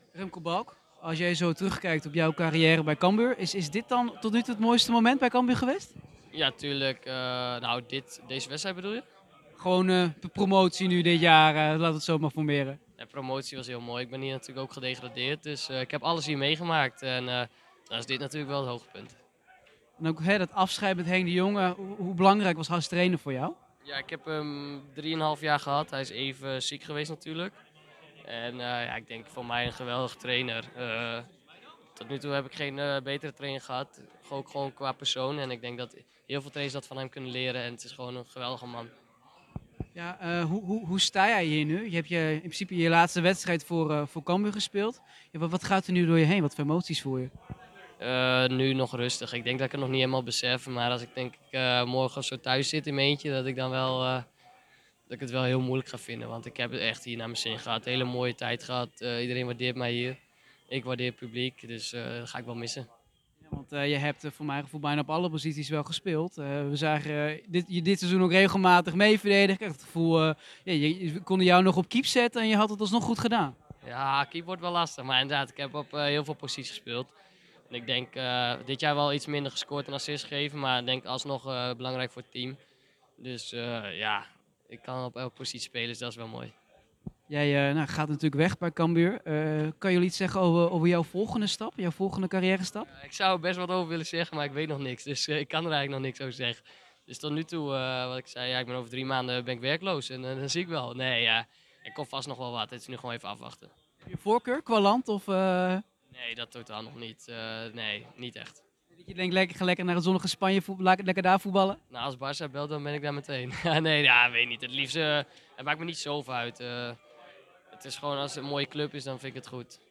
Wij spraken met hoofdrolspelers Henk de Jong, Mark Diemers en Remco Balk.